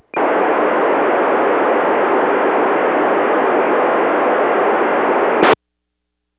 Os rádios que temos hoje podem até sintonizar uma transmissão DV, mas só ouviremos ruídos.